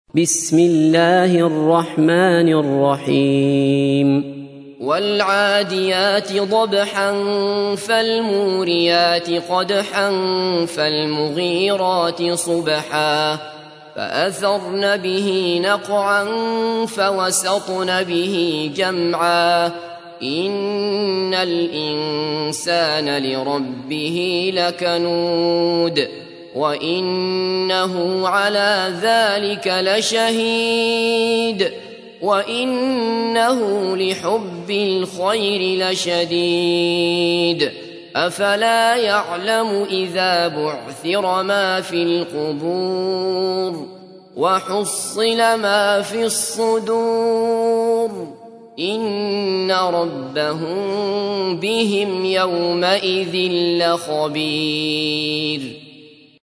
تحميل : 100. سورة العاديات / القارئ عبد الله بصفر / القرآن الكريم / موقع يا حسين